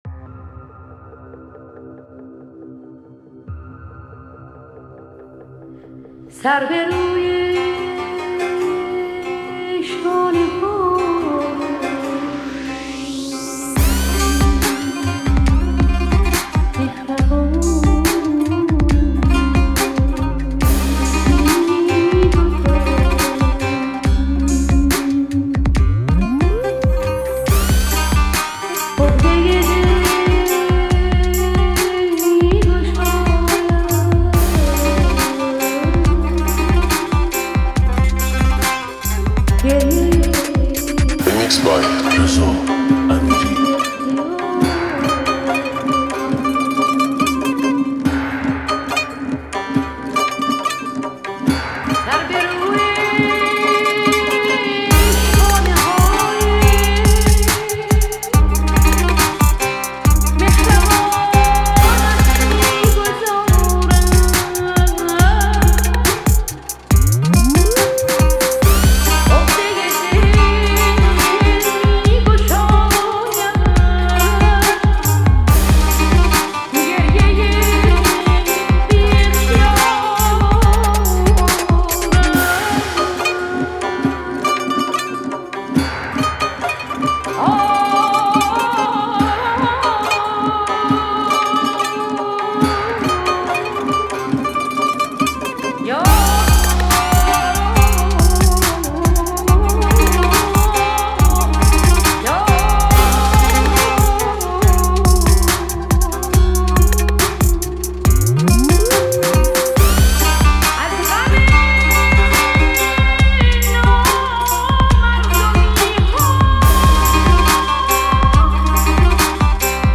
ریمیکس بیس دار
اهنگ سیستمی